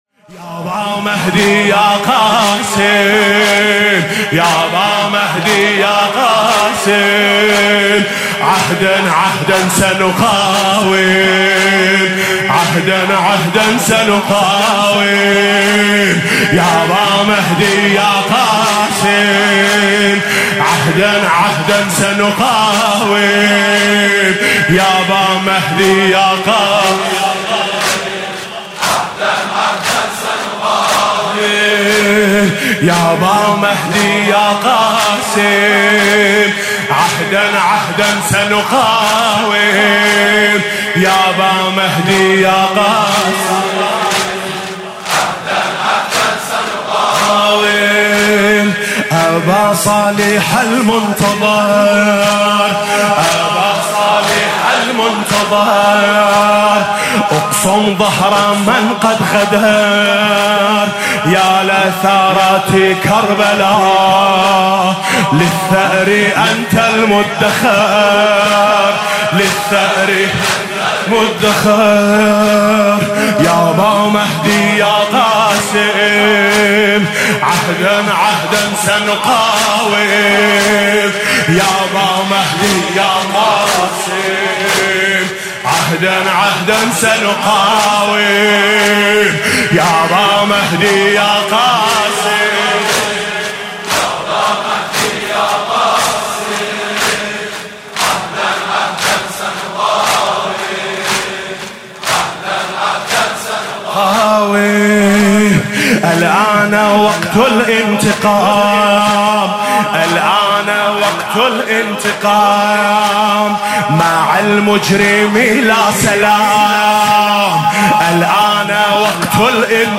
رجز‌خوانی مطیعی برای حاج قاسم / عهد می‌بندیم که مقاومت کنیم
دومین شب مراسم سوگواری شهادت حضرت زهرا (س) و شهادت سپهبد حاج قاسم سلیمانی در هیأت میثاق با شهدا با سخنرانی حجت‌الاسلام قرائتی و مدیحه‌سرایی میثم مطیعی برگزار شد.